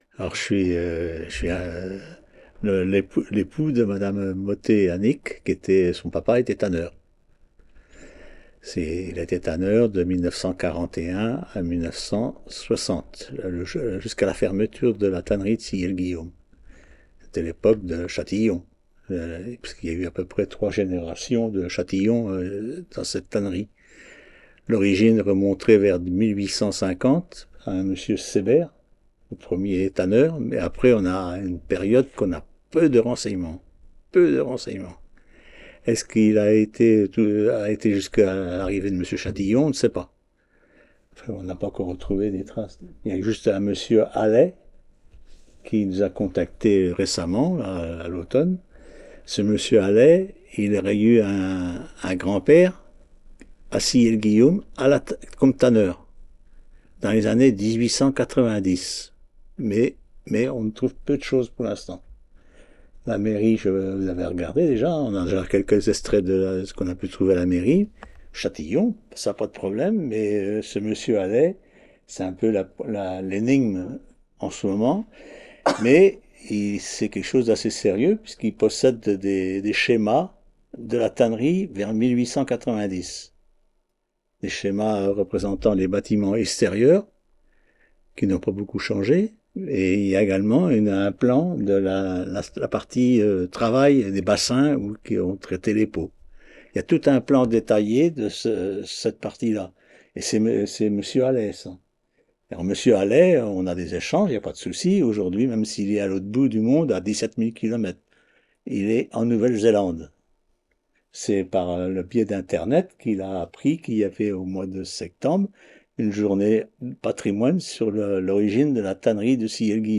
Enquête Petites Cités de Caractères - Parcours sonore Sillé
Catégorie Témoignage